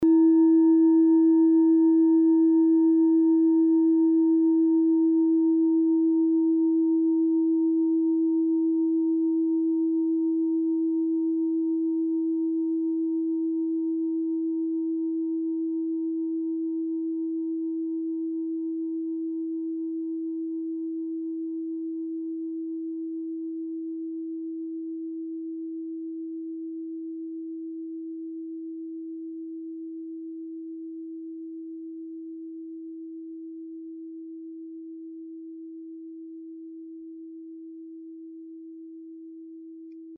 Klangschalen-Typ: Bengalen und Tibet
Klangschale 7 im Set 5
Klangschale Nr.7
(Aufgenommen mit dem Filzklöppel/Gummischlegel)
klangschale-set-5-7.mp3